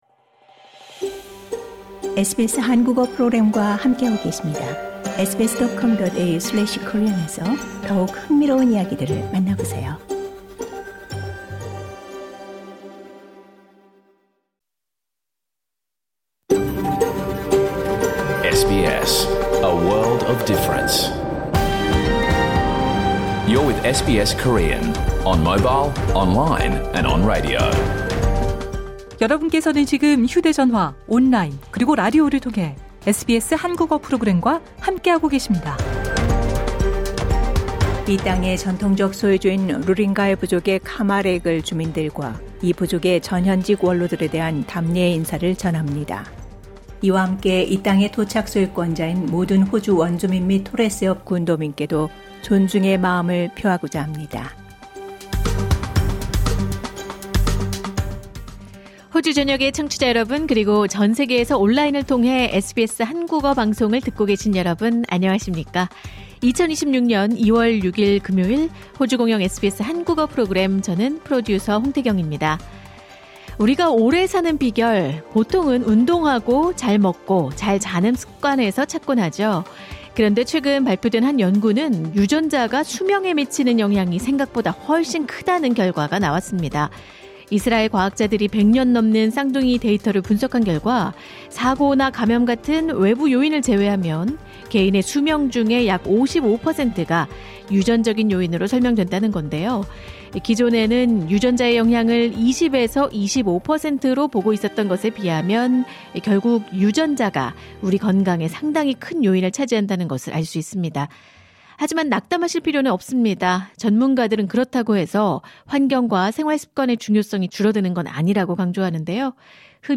2026년 2월 6일 금요일에 방송된 SBS 한국어 프로그램 전체를 들으실 수 있습니다.